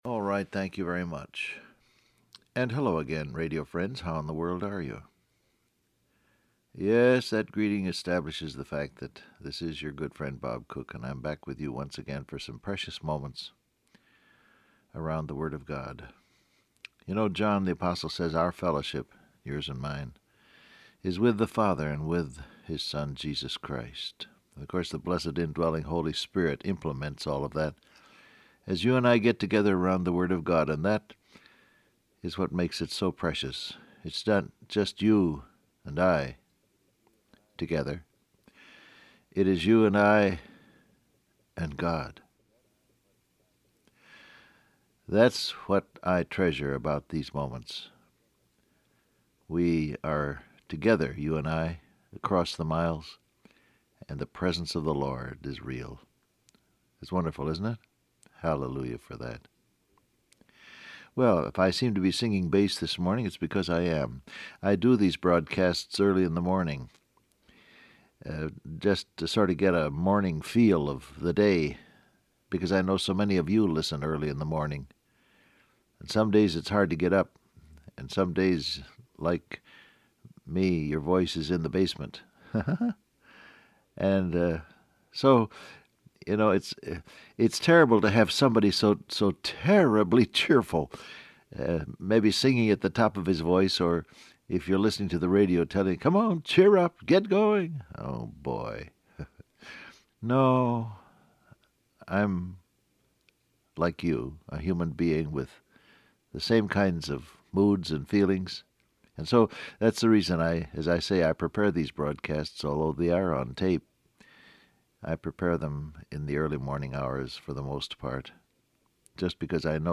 Download Audio Print Broadcast #6943 Scripture: John 16:31 , John 13, Colossians 1:27 Topics: Peace , Believe , Christ In You , Heart Rejoices Transcript Facebook Twitter WhatsApp Alright, thank you very much.